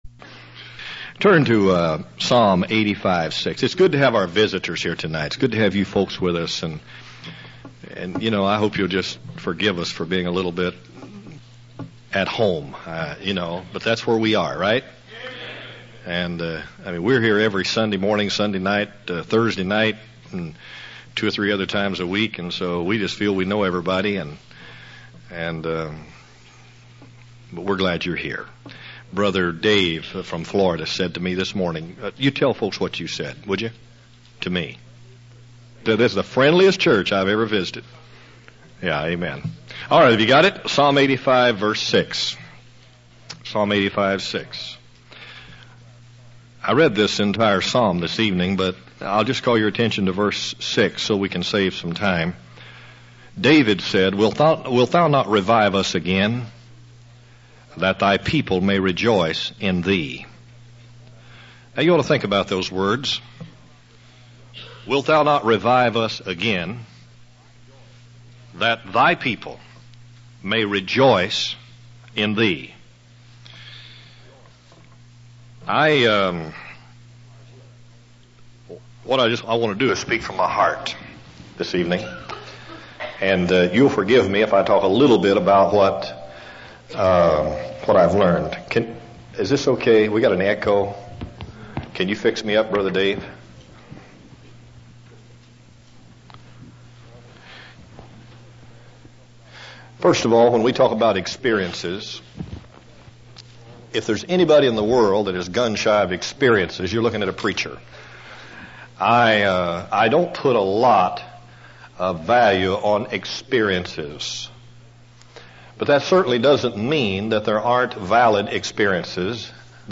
In this sermon, the preacher shares personal anecdotes and stories to illustrate profound truths about God's work in our lives.